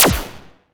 Power Laser Guns Demo
LaserGun_24.wav